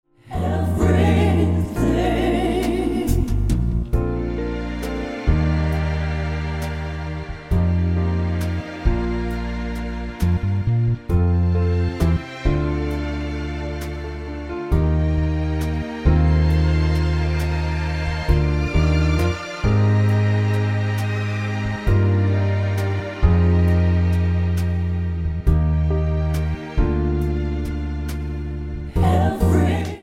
--> MP3 Demo abspielen...
Tonart:Bb-B-C mit Chor